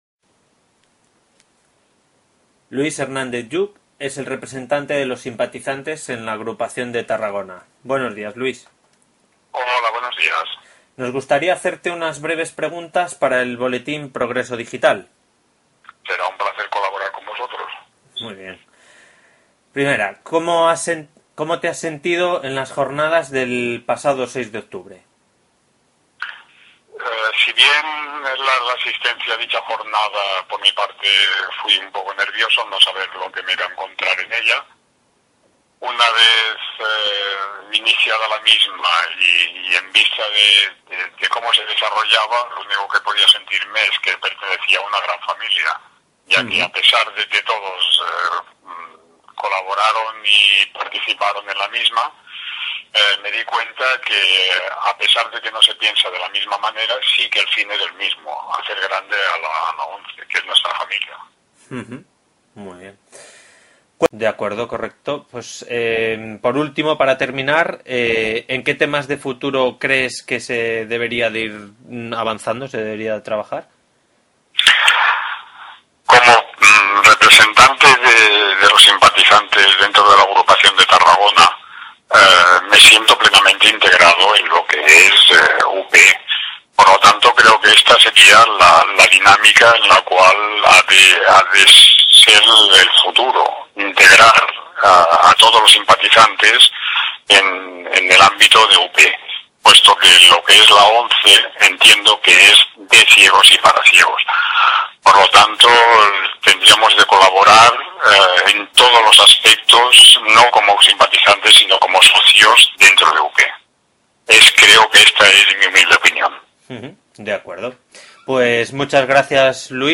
A continuación, podéis escuchar a tres simpatizantes y de su voz un resumen de dichas jornadas y de las cuestiones de futuro sobre las que quiere incidir este colectivo: